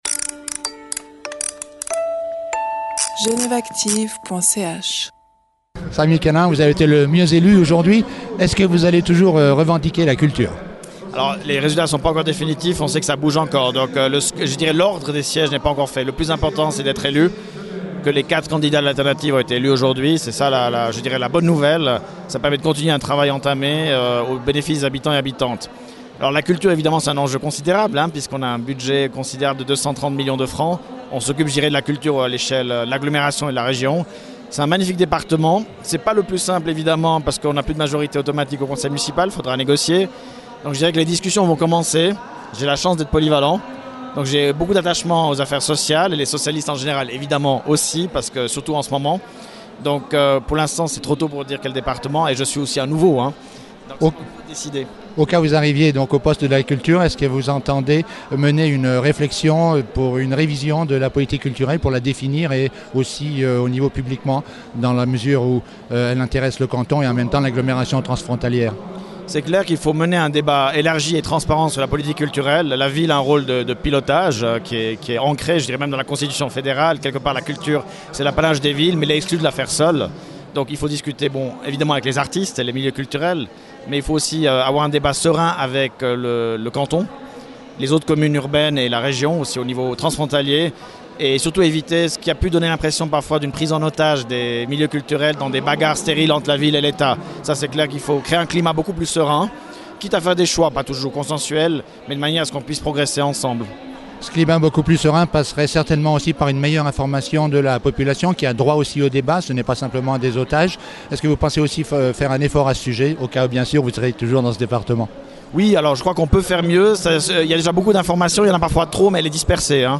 Lors de la même séance au théâtre du Loup, Sami Kanaan avait aussi confirmé sa bonne connaissance des enjeux du domaine culturel.